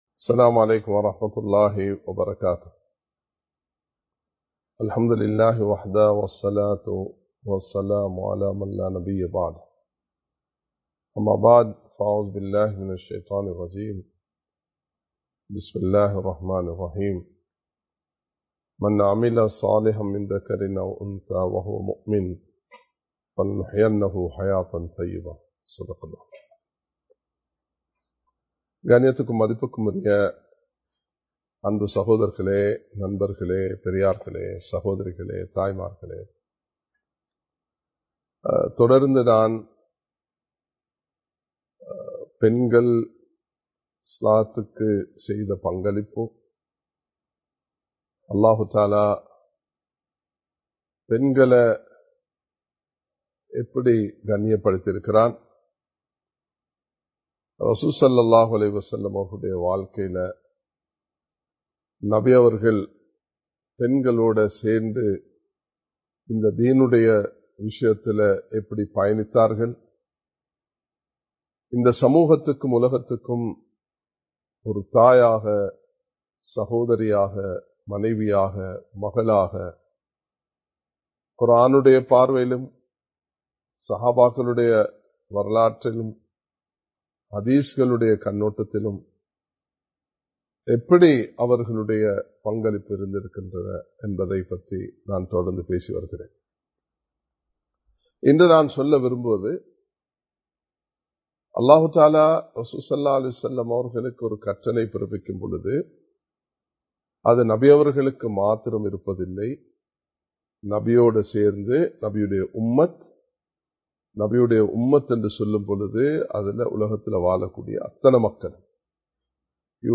இஸ்லாமிய பார்வையில் பெண்கள் (பகுதி 03) | Audio Bayans | All Ceylon Muslim Youth Community | Addalaichenai
Live Stream